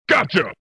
Download Gotcha MK3 Sound effect Button free on Sound Buttons